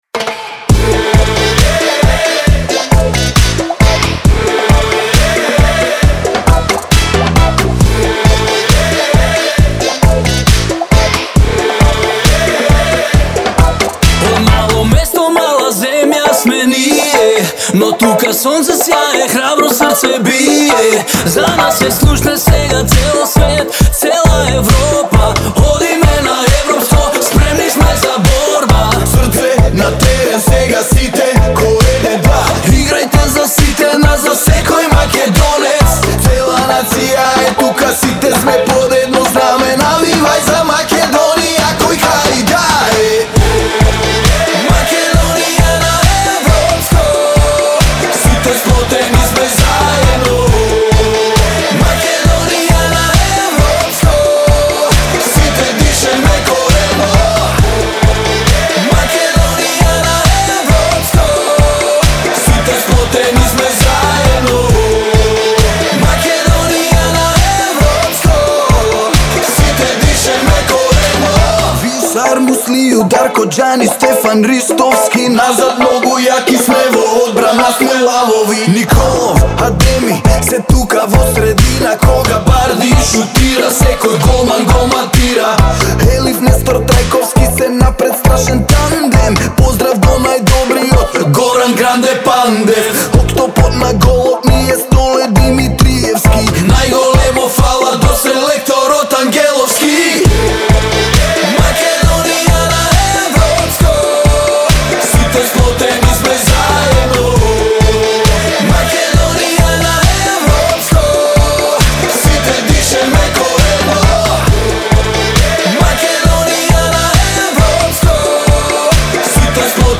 клавијатури и придружен вокал.